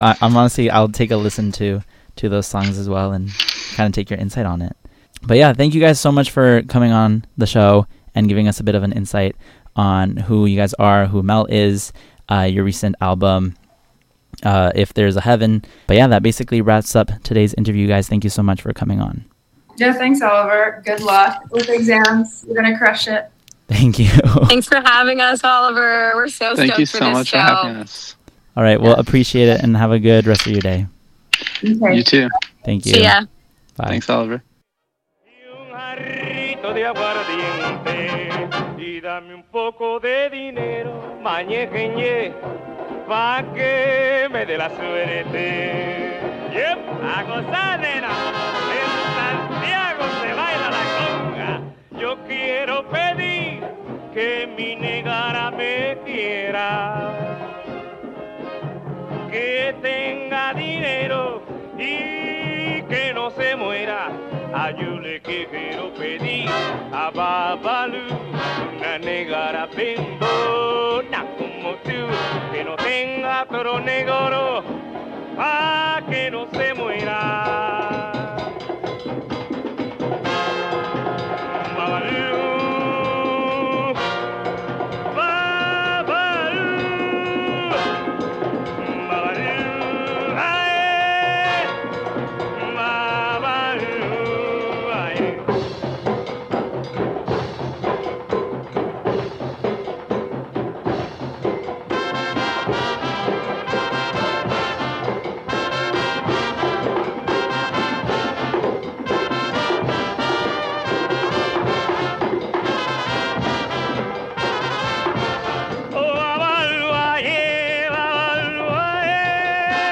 This week’s poetry reading is “One Day” by Rupert Brooke courtesy of Librivox and Project Guetenberg.